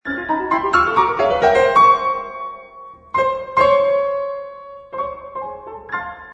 picture_piano.mp3